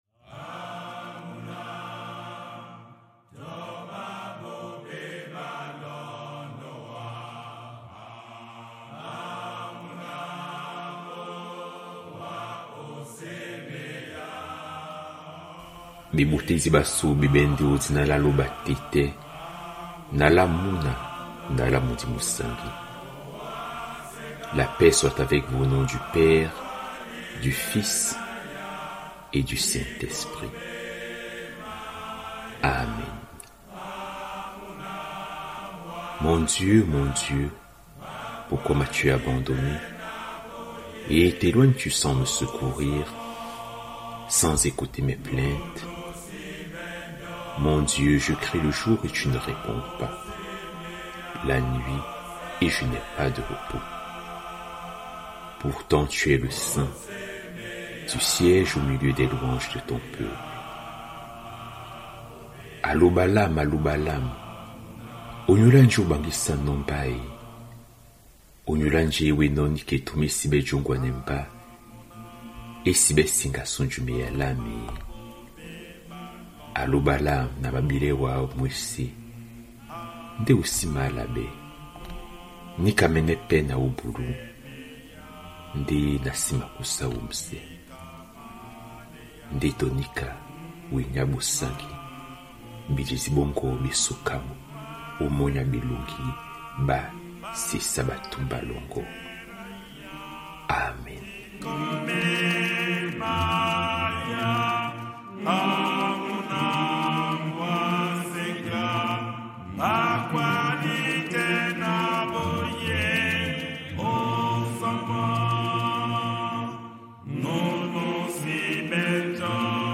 Publié dans Lectures Bibliques.